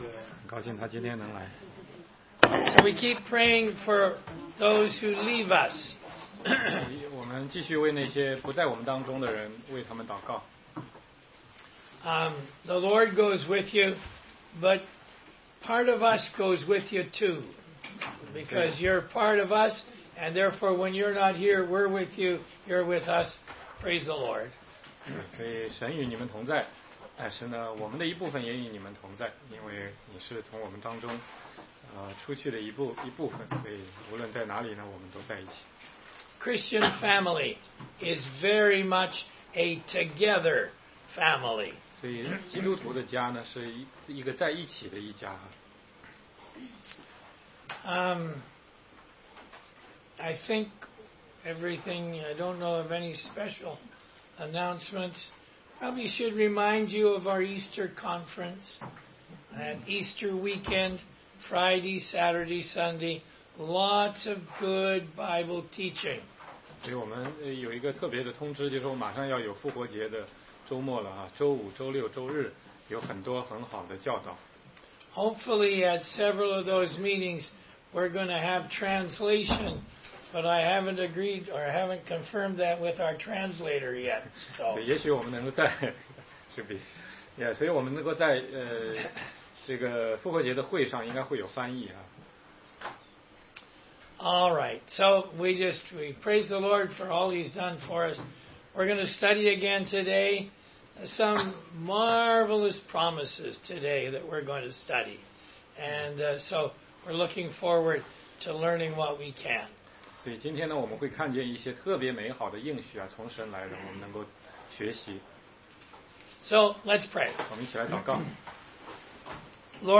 16街讲道录音 - 罗马书8章18-28节